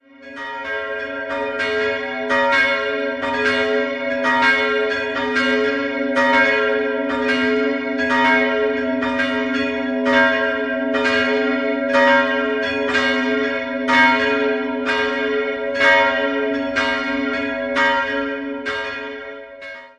3-stimmiges Geläute: b'-c''(+)-g'' Die große Glocke wurde 1695 von Hans Conrad Roth in Forchheim gegossen, die mittlere stammt aus dem 14.